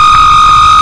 循环 " Drone1
描述：声码器的嗡嗡声载体
Tag: 循环 声码器载波 雄蜂